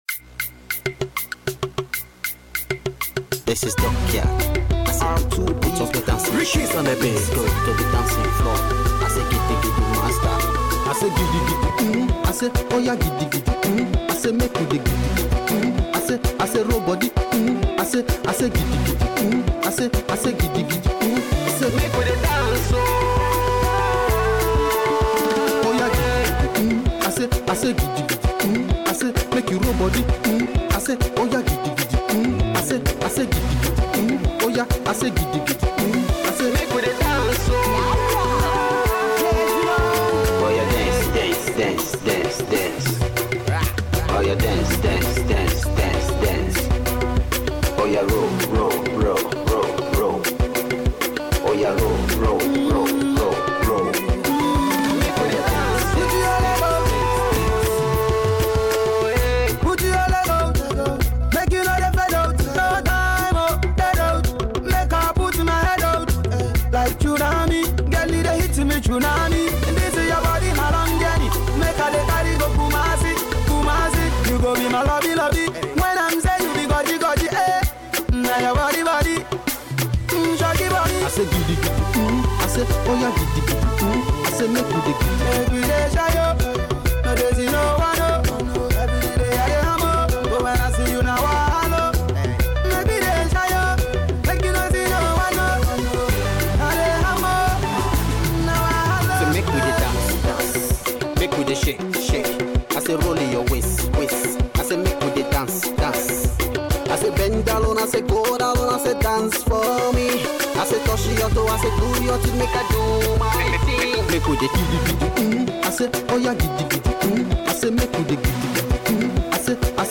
GHANA SONG